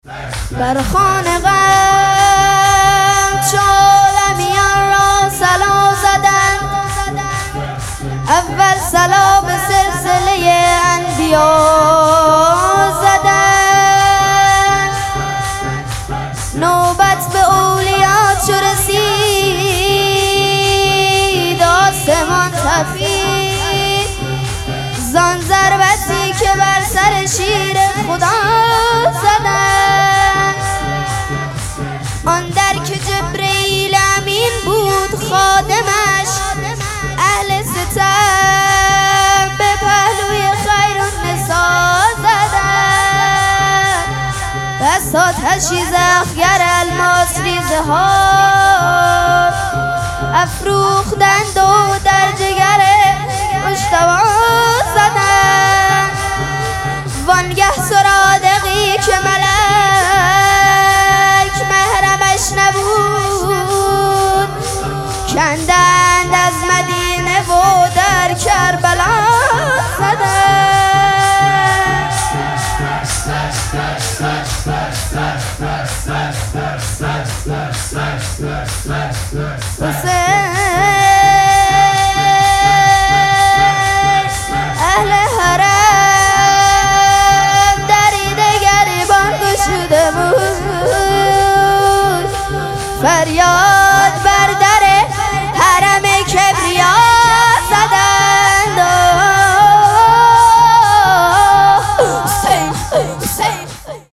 شب دوم مراسم عزاداری دهه دوم فاطمیه ۱۴۴۶
حسینیه ریحانه الحسین سلام الله علیها
شور